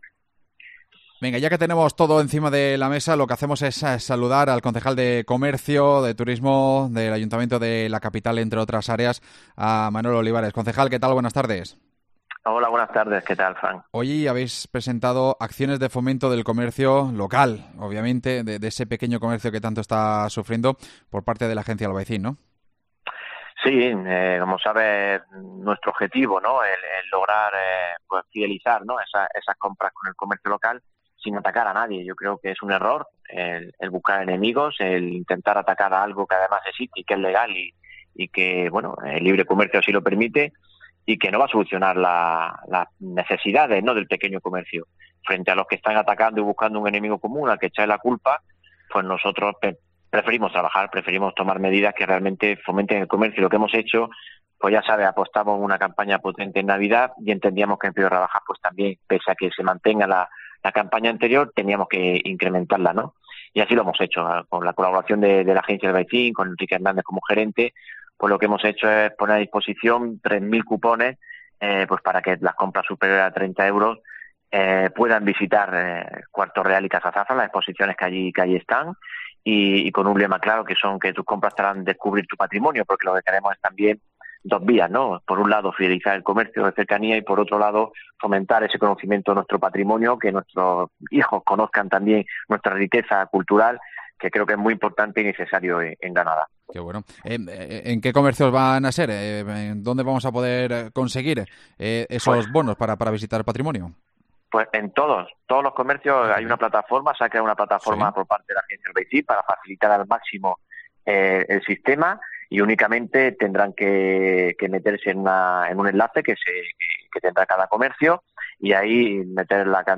AUDIO: Lo ha contado en COPE Granada el concejal de comercio, Manuel Olivares